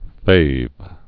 (fāv) Informal